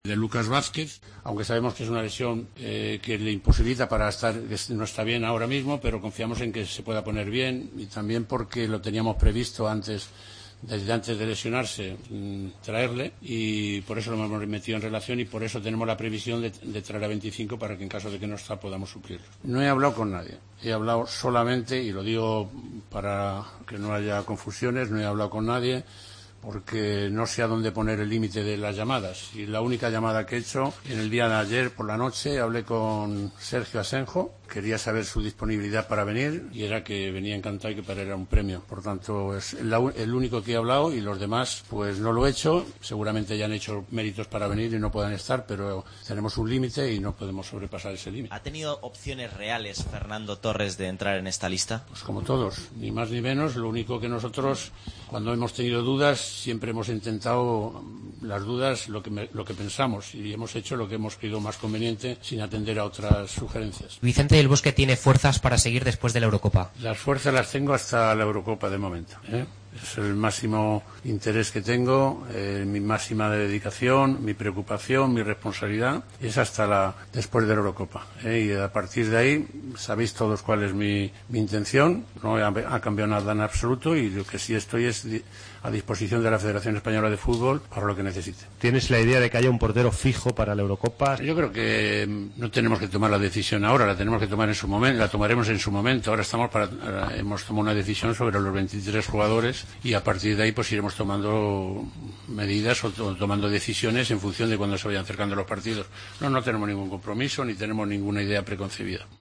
Resumen de la rueda de prensa de Del Bosque